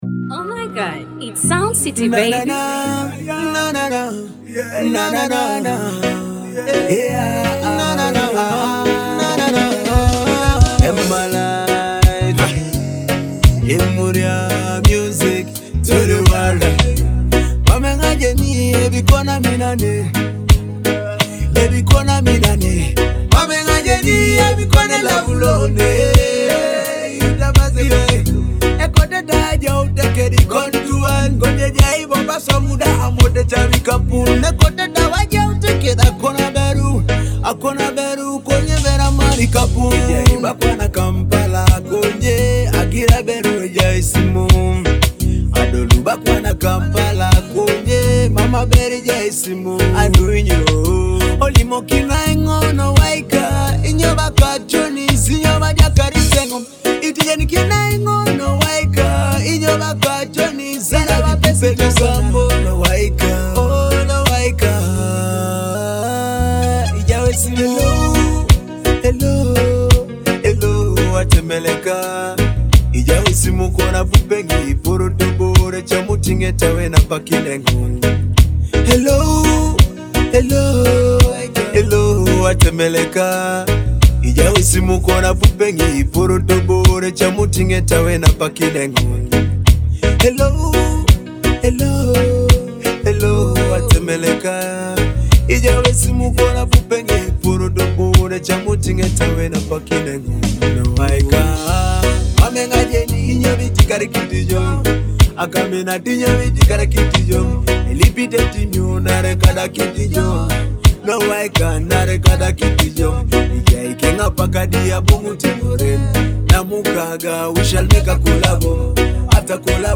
soulful reggae track